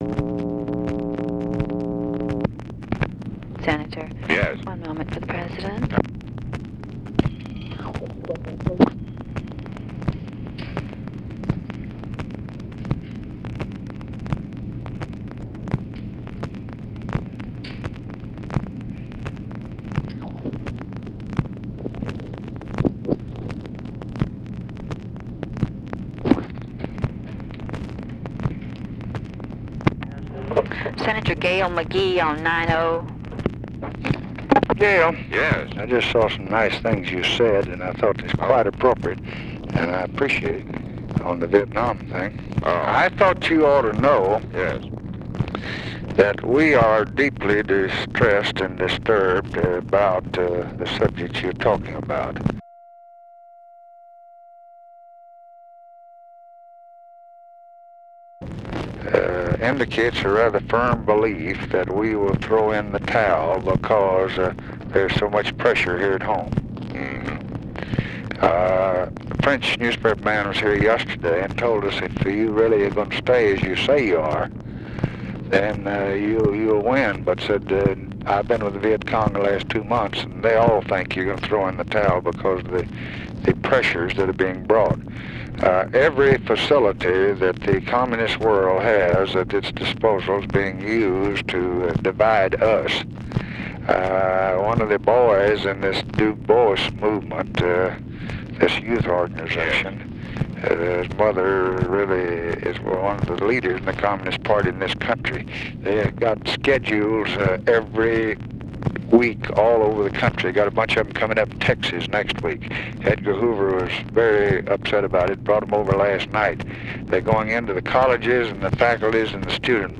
Conversation with GALE MCGEE, April 29, 1965
Secret White House Tapes